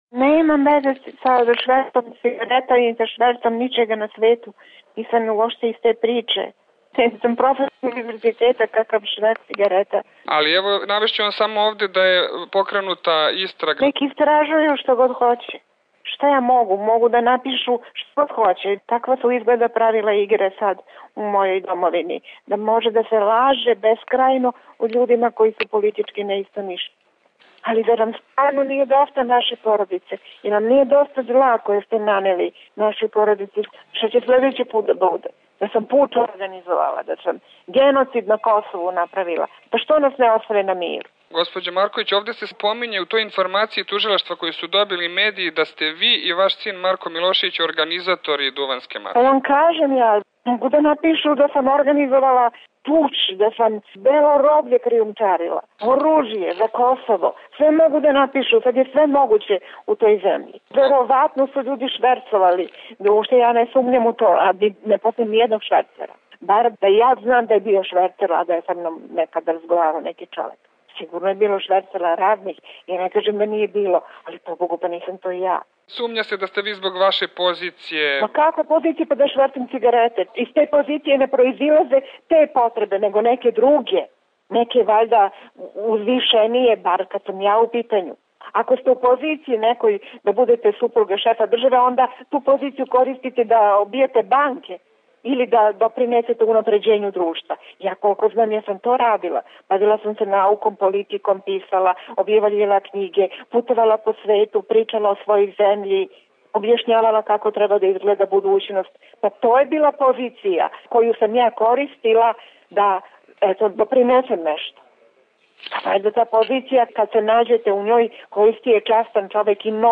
Mirjana Marković, intervju za RSE 13.06.2007.